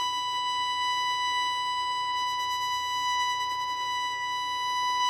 以不同的表达方式演奏长的持续音符
用一对Neumann话筒进行录音
标签： 音符 音调 刺耳 维持 小提琴
声道立体声